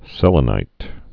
(sĕlə-nīt, sĭ-lē-)